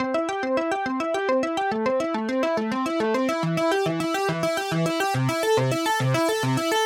转场过渡音效
描述：咆哮的声音，对视频和游戏非常有用 。我在软件示例声音上使用反向效果。
标签： 转变 过渡 音效 瞬移 过境 转场
声道立体声